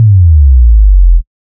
Slide Down BASS{SSO}.wav